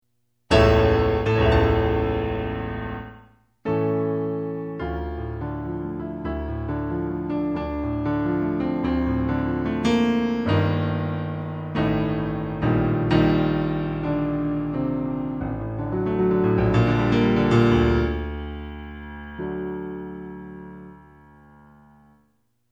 D minor